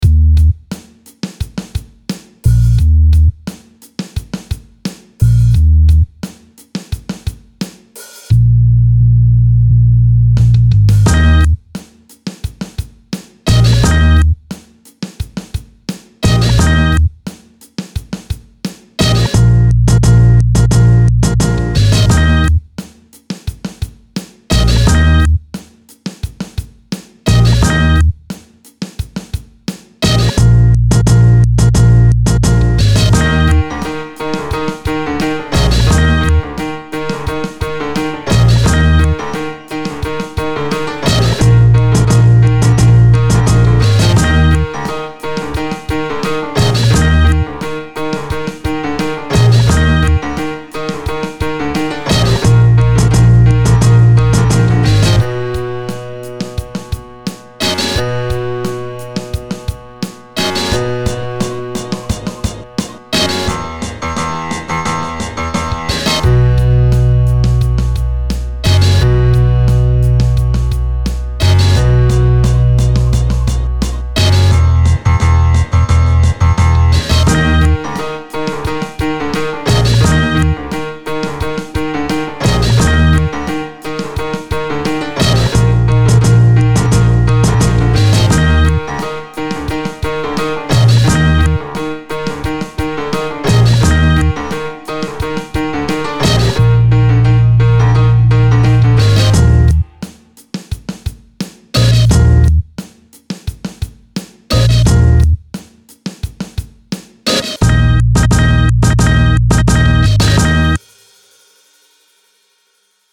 Some hip-hop, why not?